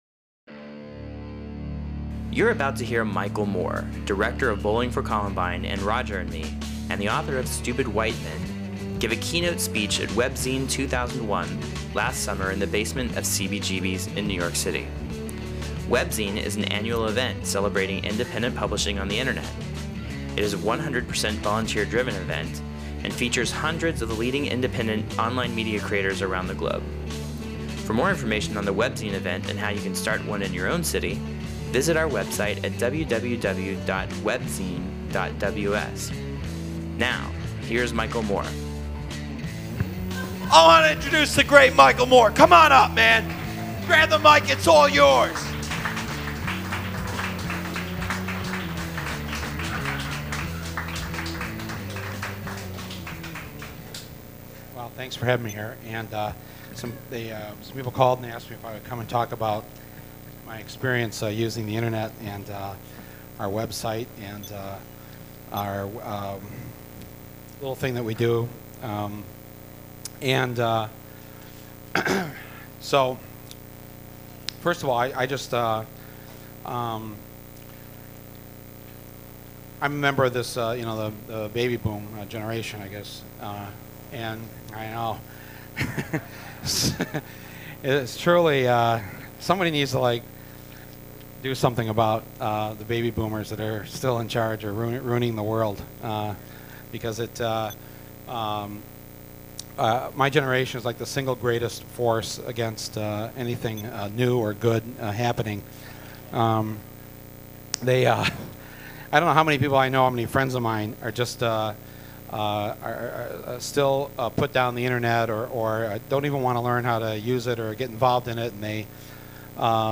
Michael Moore gave a keynote speech on July 21, 2001 for Webzine 2001 in New York City. He spoke about the Internet as a revolutionary medium that can empower the little guy, how the Internet helped TV Nation win another season, a powerful scene from Bowling for Columbine, a dirty little chat with Bill Clinton at a porto-potty and a few inspirational stories to motivate YOU to get out there and do something.